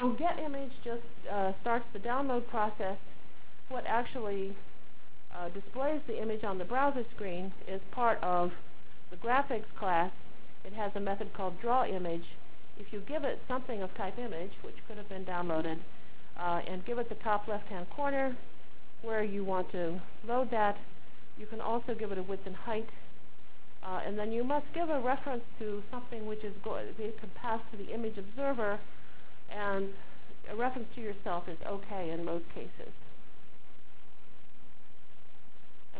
From Jan 29 Delivered Lecture for Course CPS616 -- Java Lecture 3 -- Exceptions Through Events CPS616 spring 1997 -- Jan 29 1997.